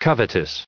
added pronounciation and merriam webster audio
181_covetous.ogg